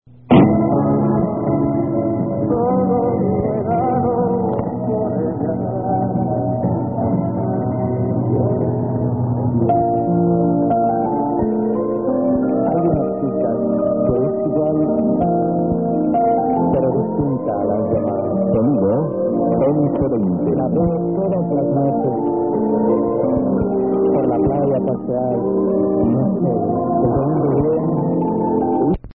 All recordings were made in Santa Barbara, Honduras (SB) using a Yaesu FRG-7 receiver.